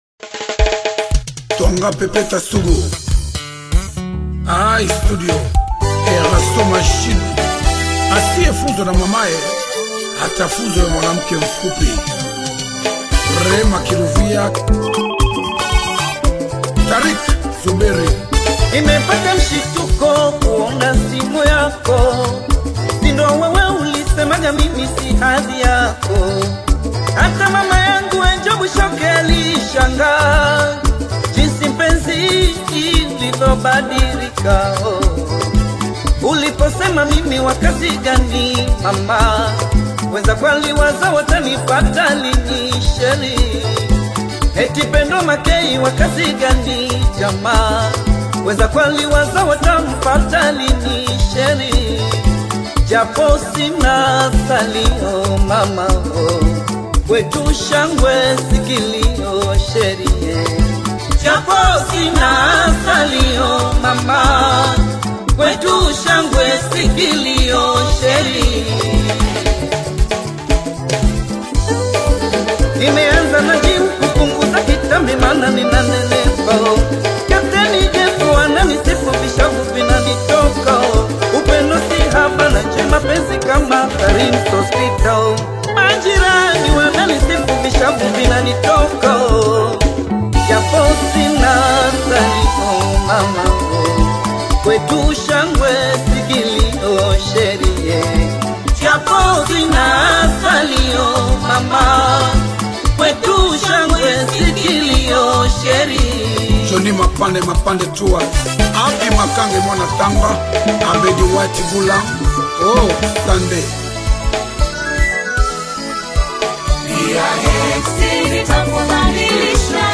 AudioRhumba